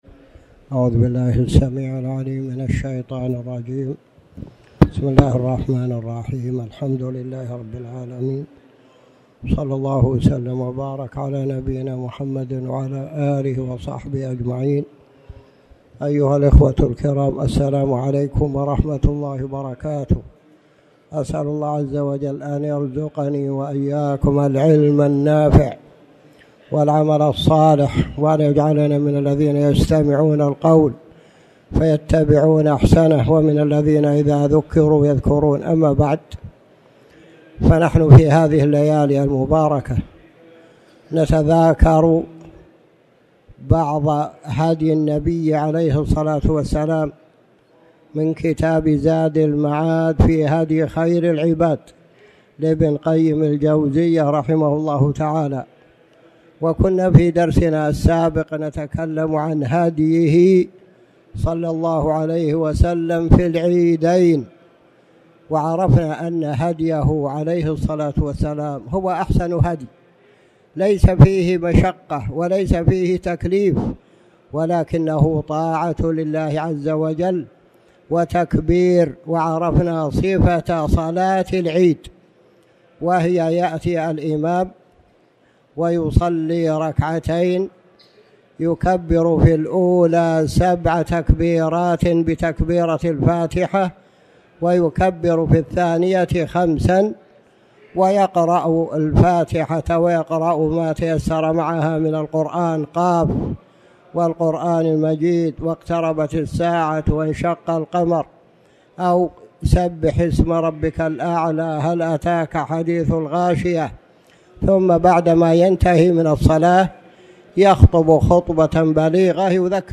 تاريخ النشر ٢٩ ذو الحجة ١٤٣٩ هـ المكان: المسجد الحرام الشيخ